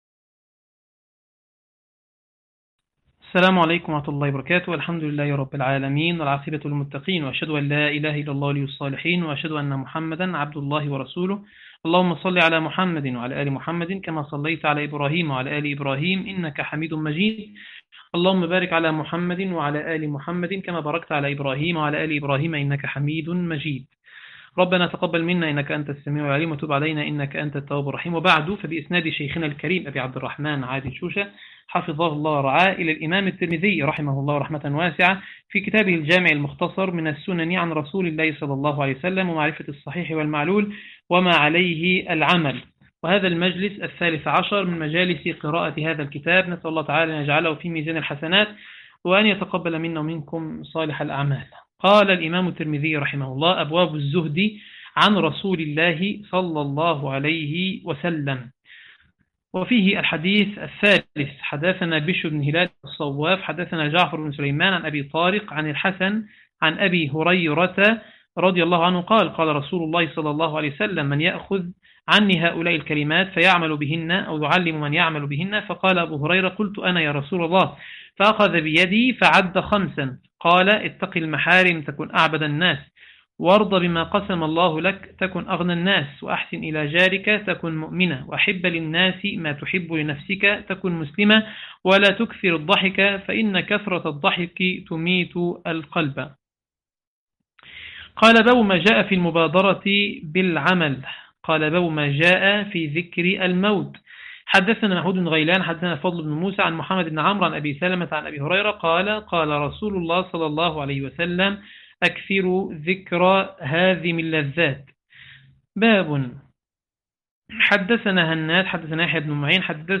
عنوان المادة المجلس 13 - قراءة سنن الإمام الترمذي تاريخ التحميل الثلاثاء 12 اغسطس 2025 مـ حجم المادة 17.84 ميجا بايت عدد الزيارات 58 زيارة عدد مرات الحفظ 53 مرة إستماع المادة حفظ المادة اضف تعليقك أرسل لصديق